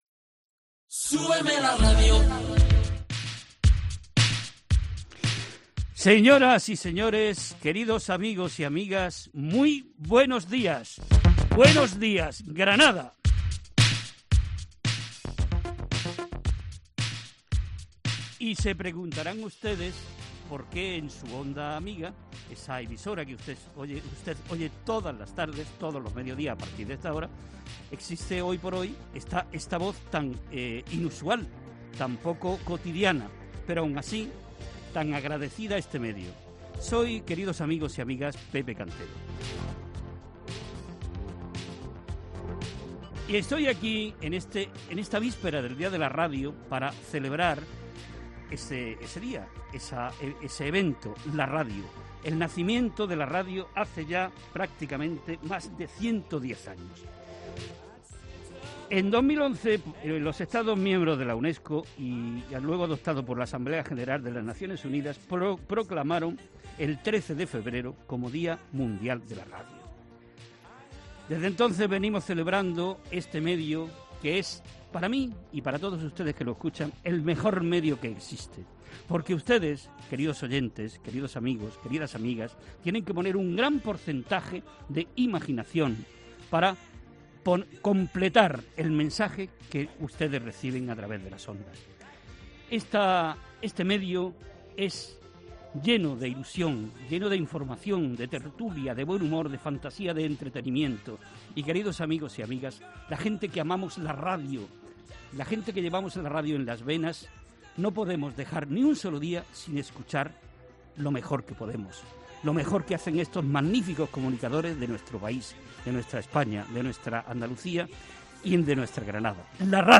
DÍA DE LA RADIO - Así ha sido la emisión de COPE, Canal Sur, RNE, SER y Onda Cero en directo